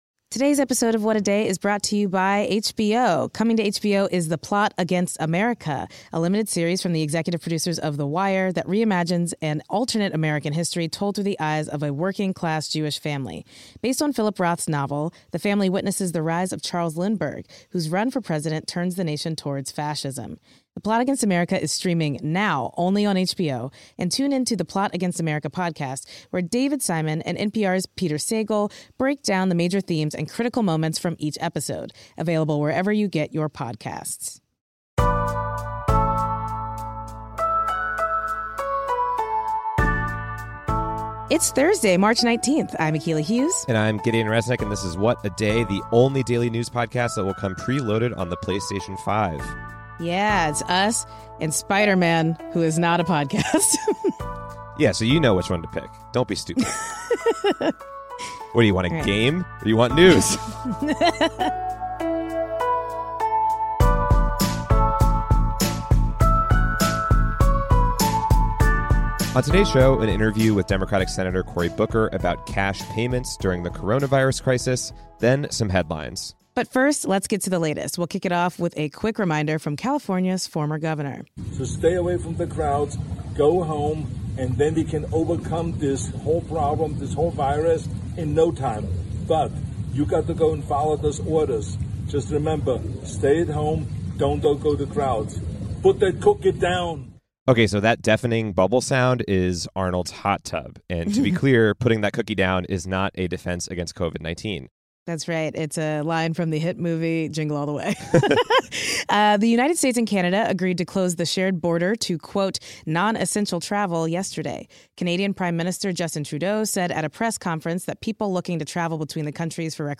Senator Cory Booker joins us to discuss a proposal he’s drafted with fellow senators to give payments to Americans to help them through the pandemic, and how the current situation reveals the flaws in America’s safety net systems.